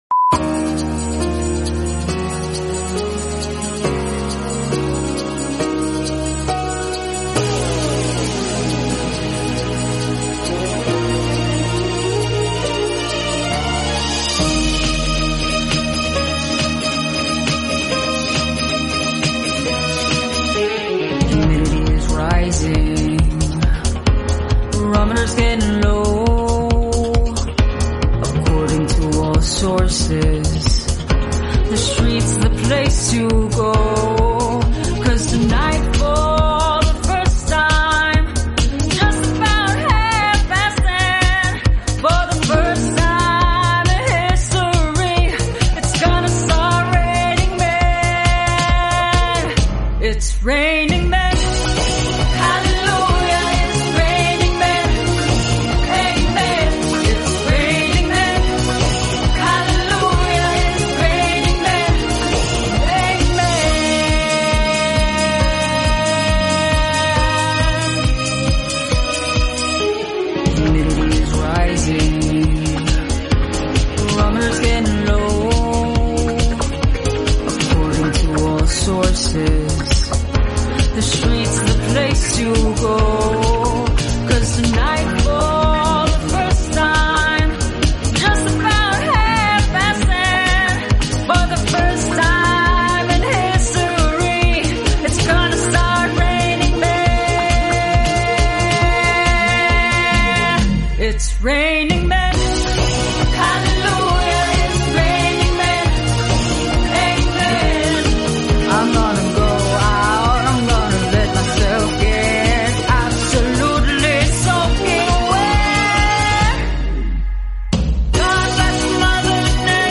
bold vocals in the booth!
the studio couldn't contain the energy!